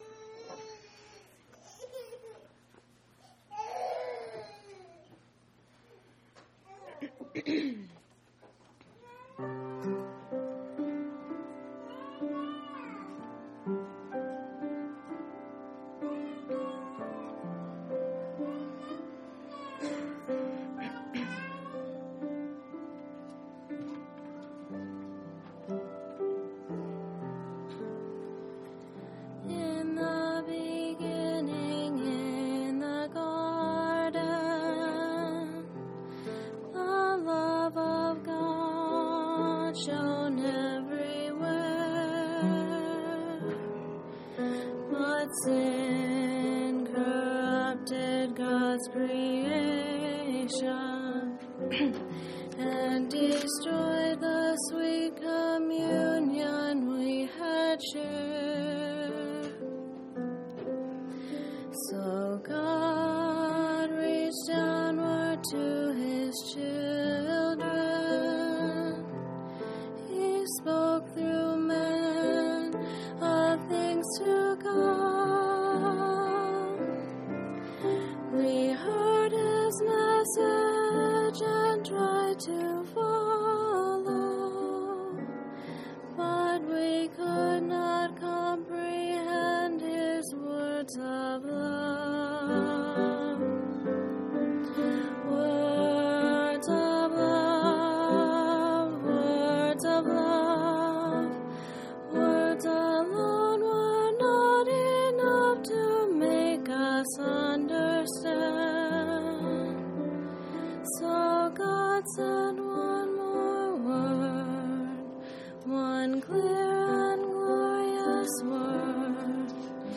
10/24/2004 Location: Phoenix Local Event